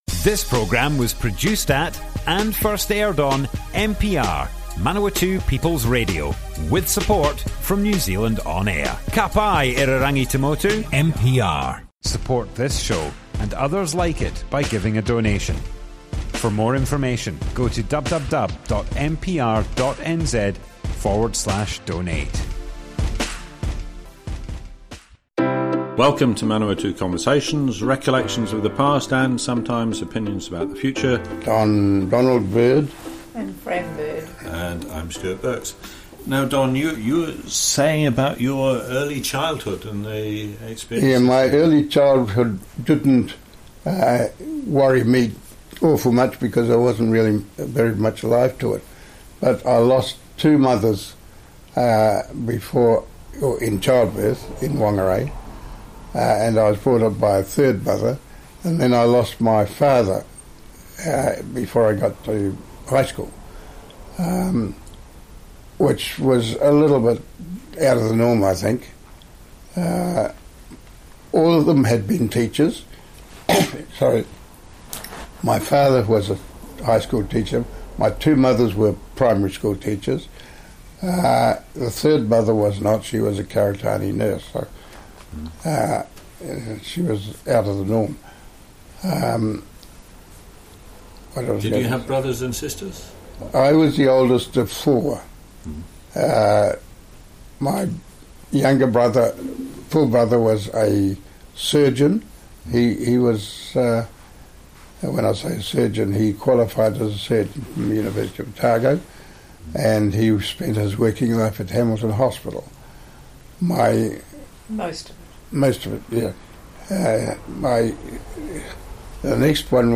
Manawatu Conversations More Info → Description Broadcast on Manawatu People's Radio 18th February 2020.
oral history